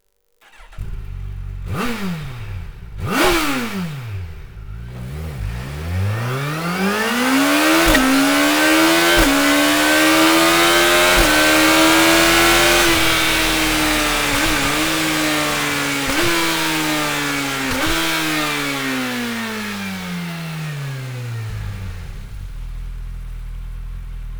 Tiefer Racing-Sound, der perfekt zu den sportlichen Fähigkeiten des Bikes passt.
104.7 dB/5500 rpm
Sound Akrapovic Racing-Line